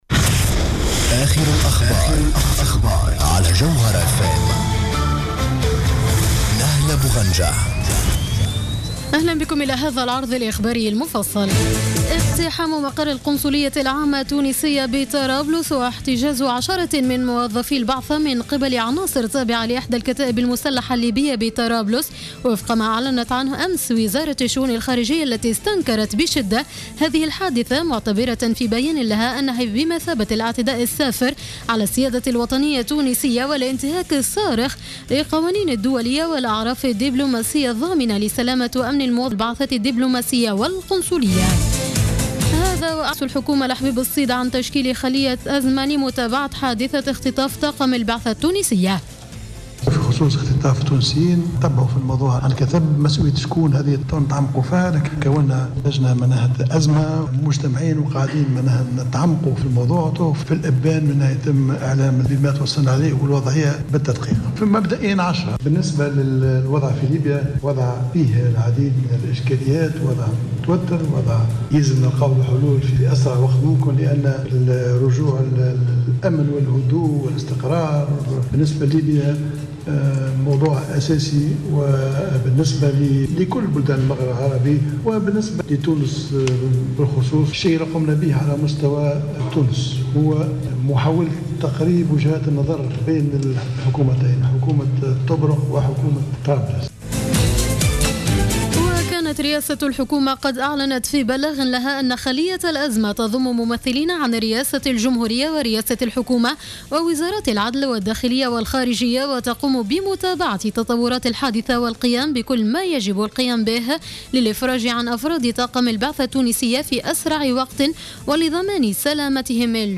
نشرة أخبار منتصف الليل ليوم السبت 13 جوان 2015